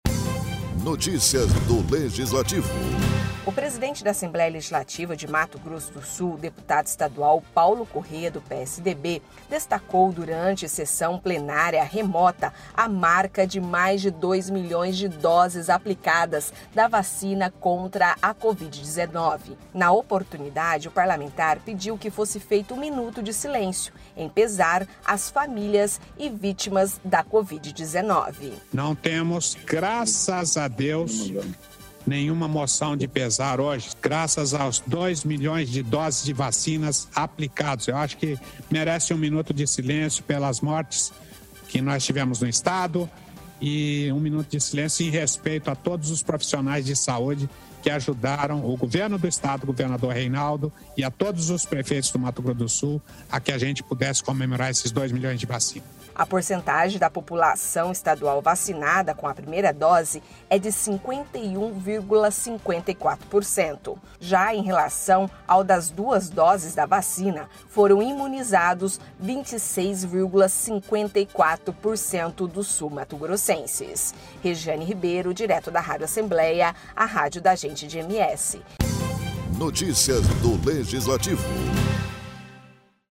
O presidente da Assembleia Legislativa de Mato grosso do Sul, deputado Paulo Corrêa, durante sessão plenária, destacou a marca de mais de dois milhões de doses aplicadas da vacina contra a covid-19 e pediu que fosse feito um minuto de silêncio em pesar as famílias e vítimas do novo coronavírus.